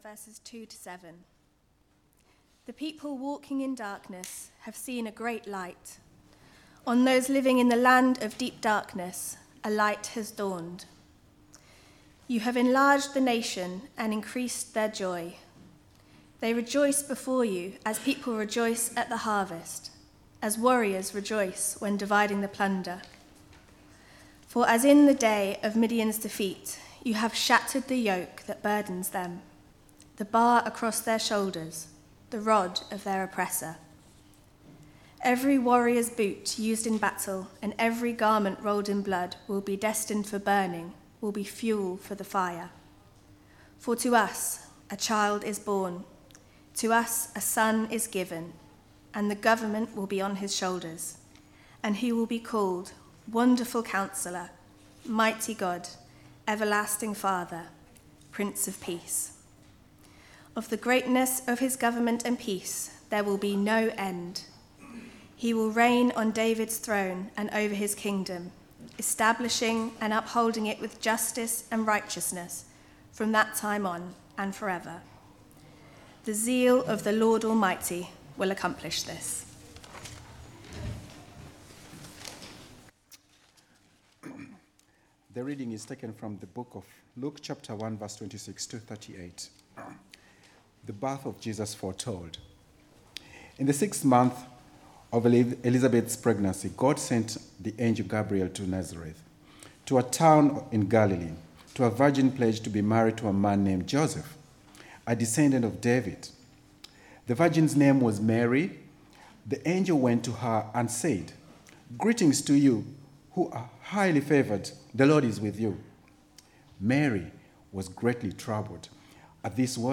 Carol Service